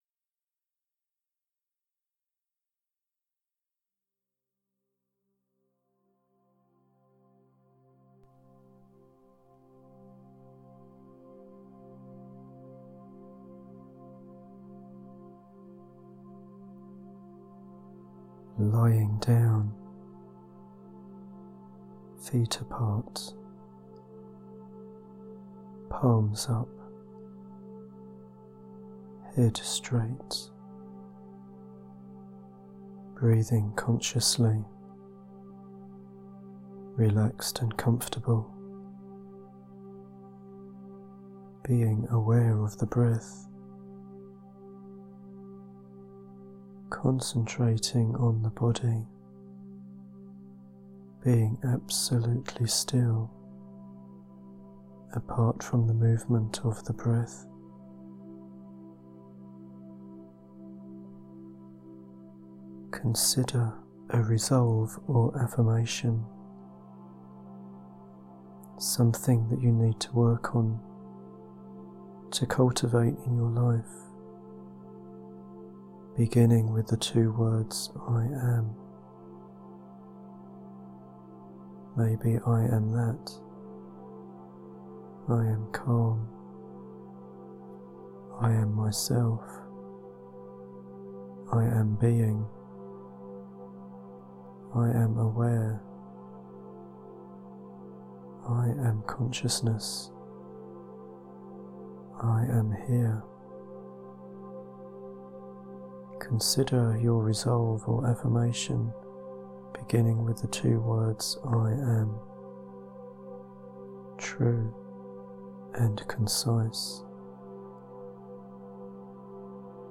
Yoga-Nidra-22-min.mp3